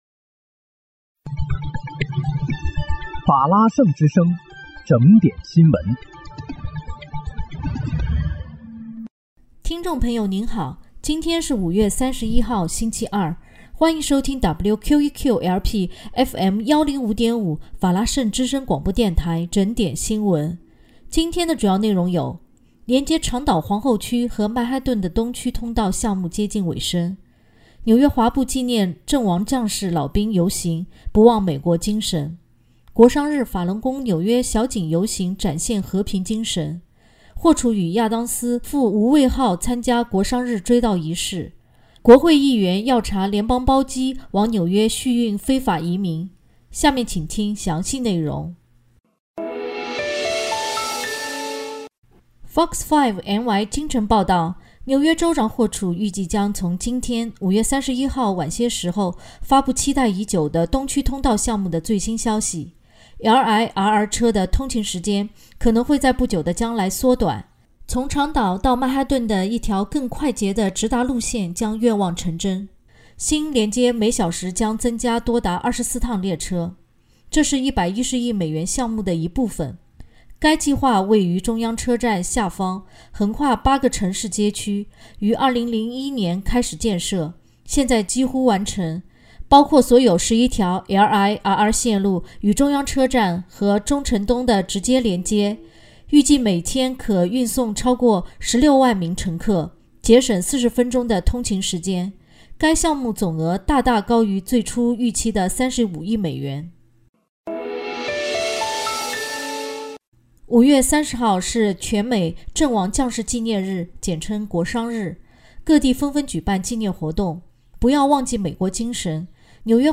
5月31日（星期二）纽约整点新闻